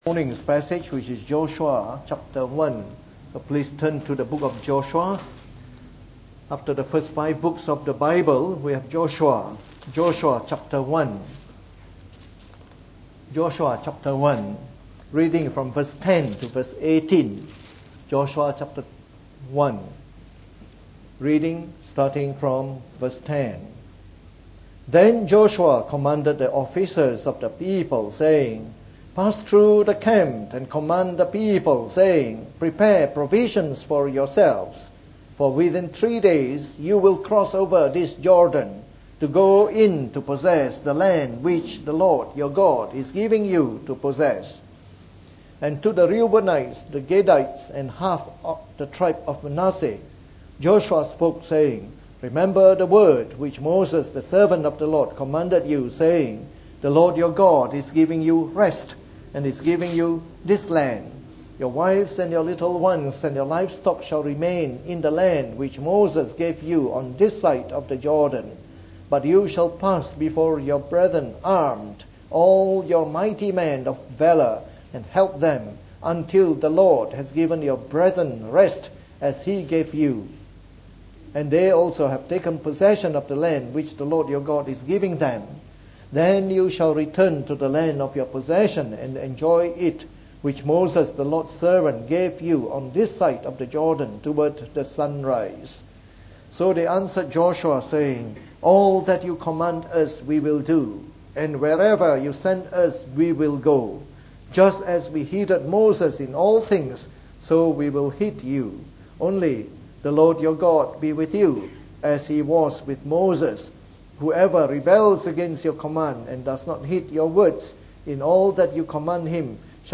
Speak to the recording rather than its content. From our series on the Book of Joshua delivered in the Morning Service.